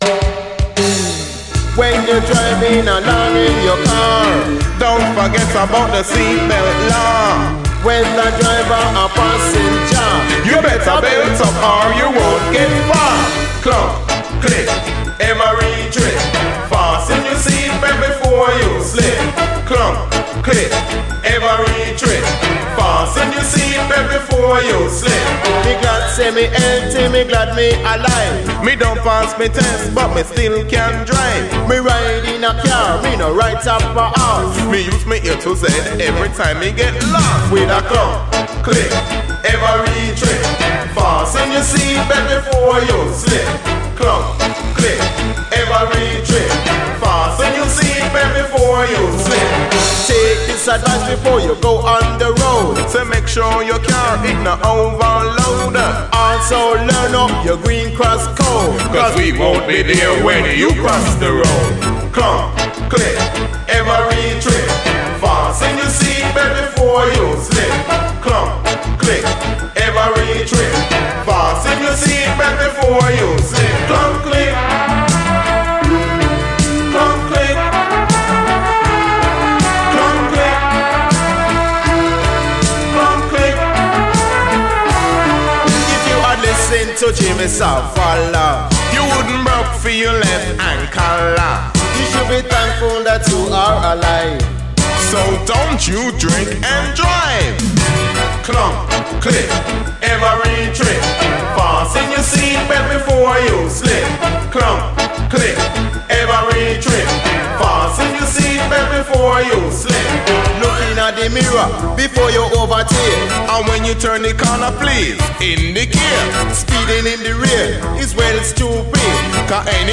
REGGAE
ゴキゲンな最高掛け合いDEE-JAYチューン！
後半はダブ。